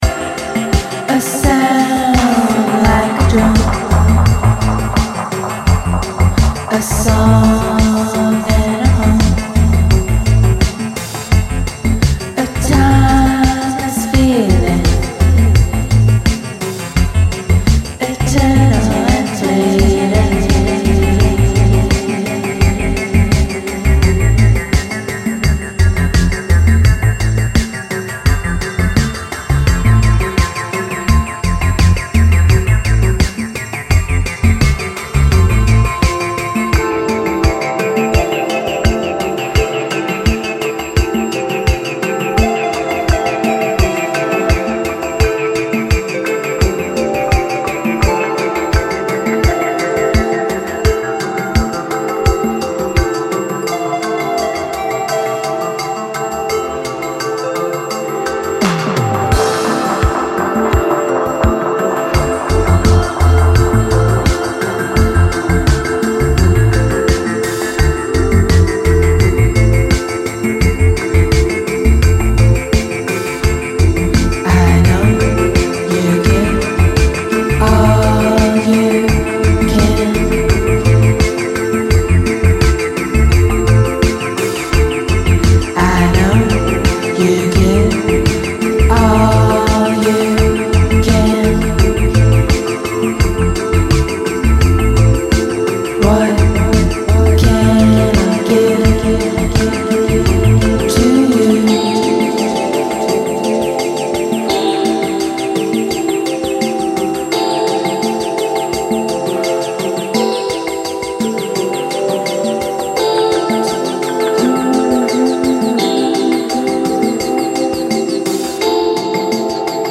スローモーなバレアリック・アシッド・トラックにドリーミーなメロディーとヴォーカルが溶け込んでいったA面